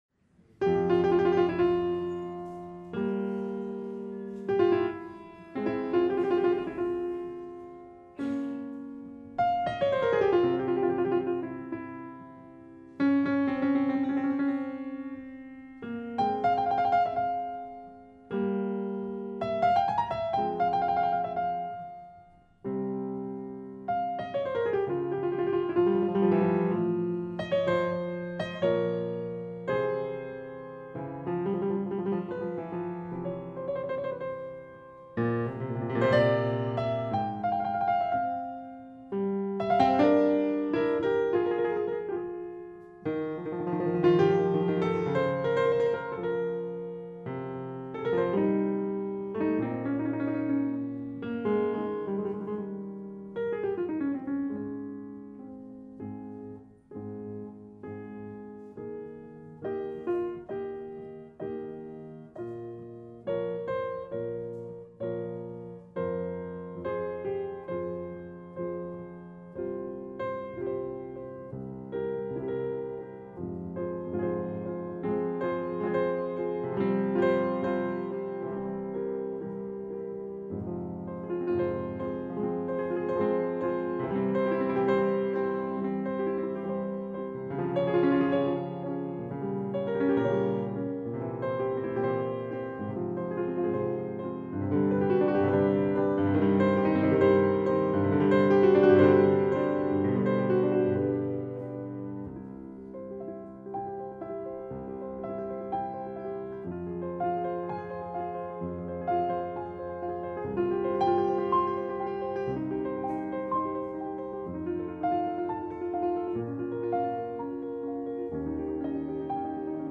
piano
Inicio de la Sonata XI en fa menor.
sonata-11-en-fa-m.mp3